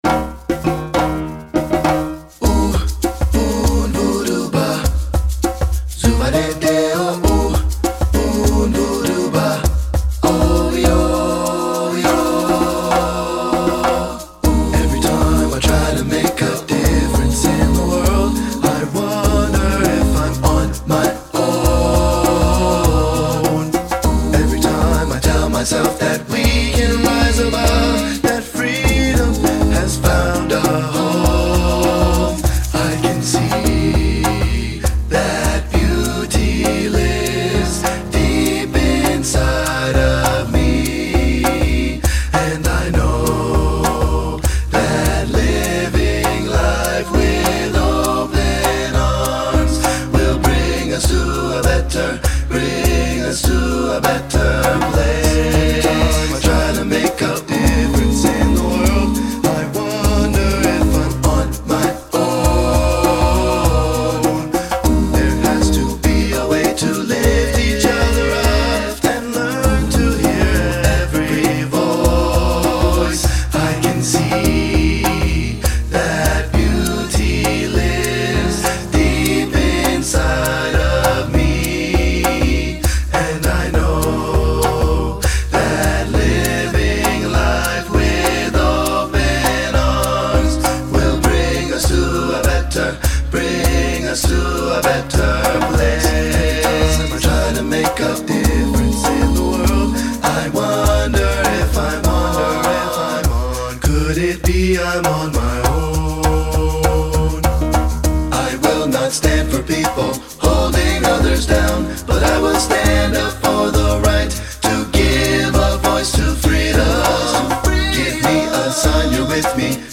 Choral Concert/General Graduation/Inspirational Male Chorus
TTBB
TTBB Audio